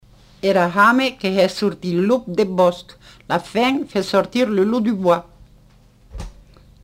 Lieu : Montauban-de-Luchon
Effectif : 1
Type de voix : voix de femme
Production du son : récité
Classification : proverbe-dicton